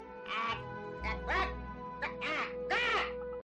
Attack Sound Effects MP3 Download Free - Quick Sounds